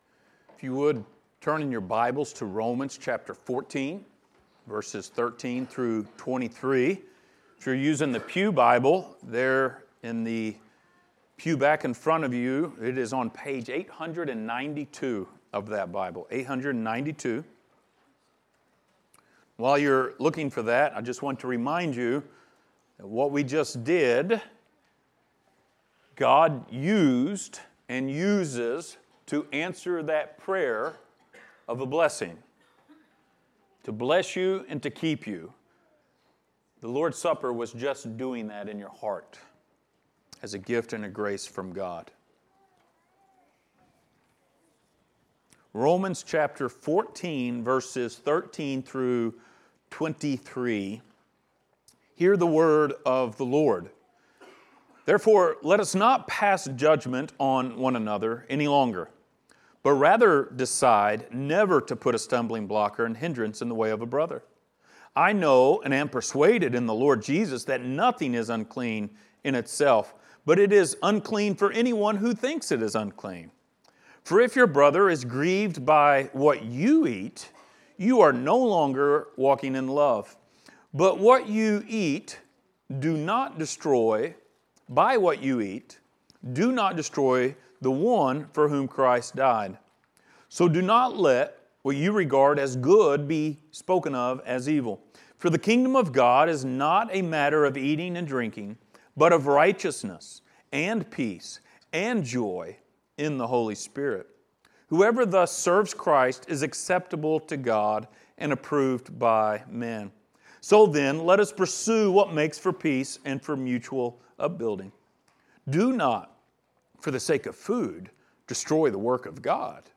Passage: Romans 14:13-23 Service Type: Sunday Morning